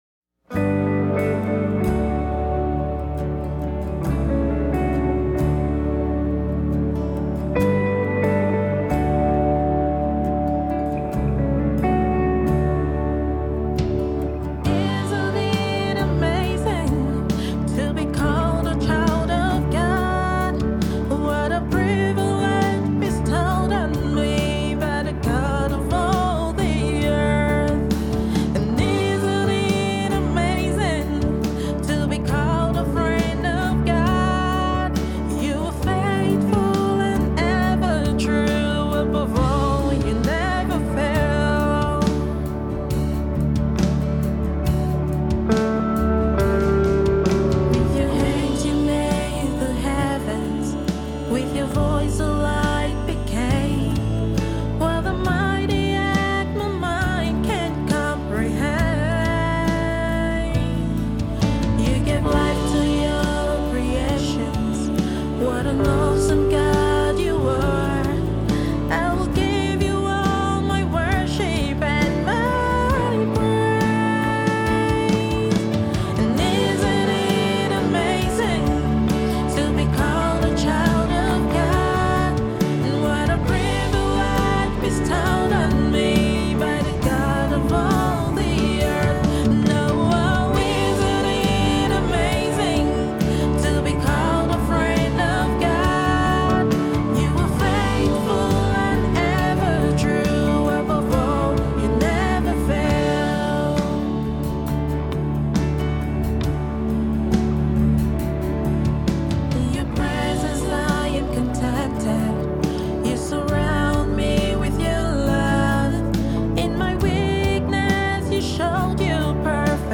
gospel music
natural female Tenor singer